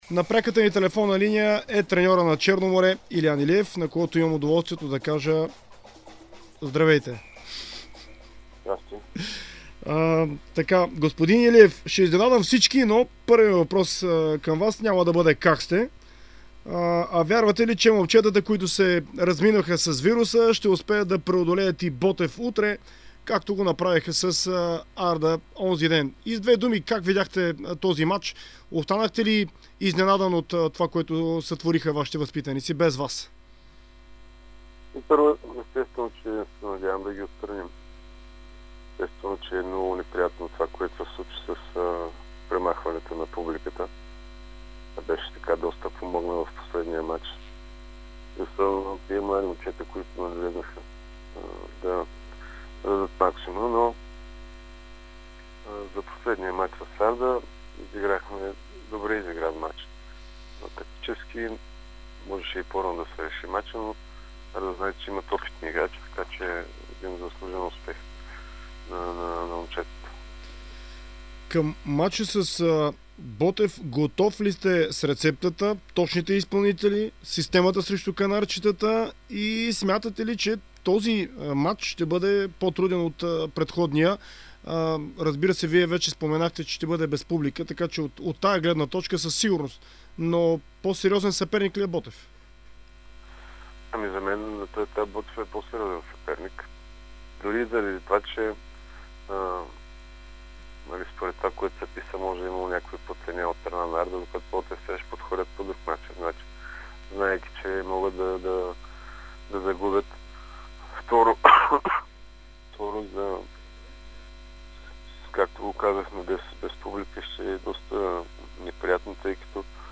Треньорът на Черно море Илиан Илиев даде специално интервю за dsport и Дарик радио. Той коментира победата на тима му срещу Арда.